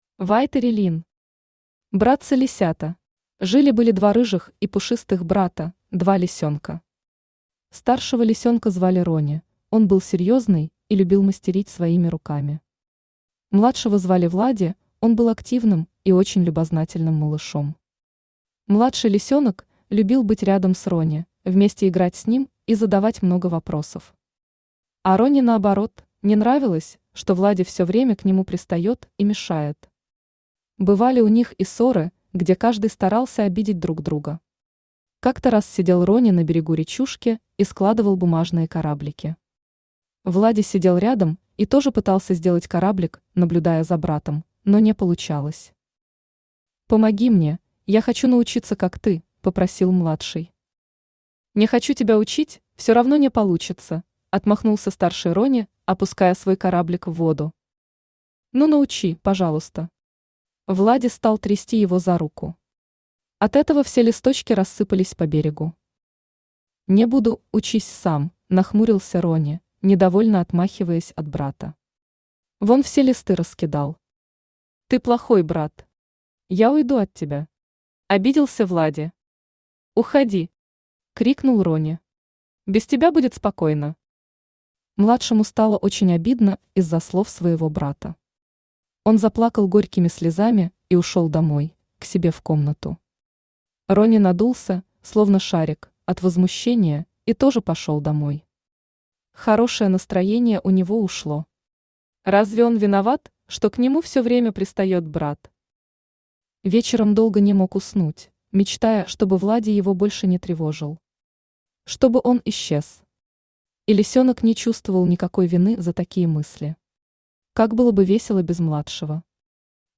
Аудиокнига Братцы лисята | Библиотека аудиокниг
Aудиокнига Братцы лисята Автор Вайтори Лин Читает аудиокнигу Авточтец ЛитРес.